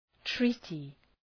Προφορά
{‘tri:tı}